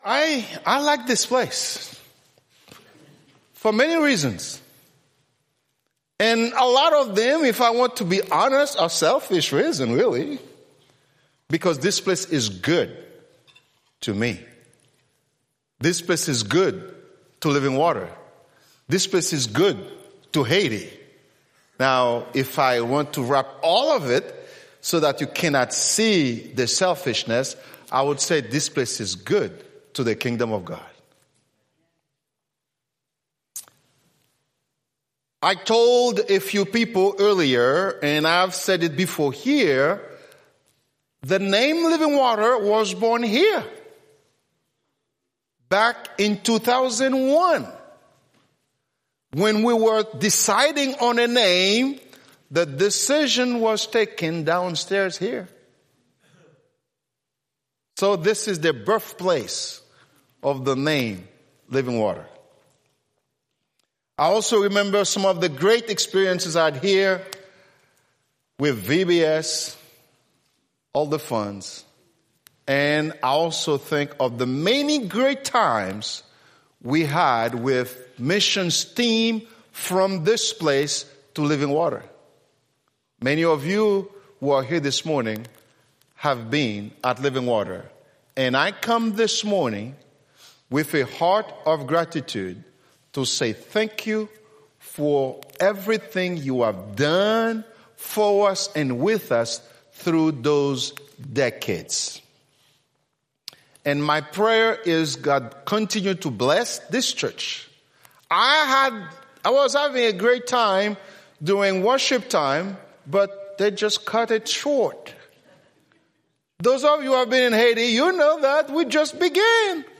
Right Click to download the audio of this message or Click to play in your browser 2025 Sermons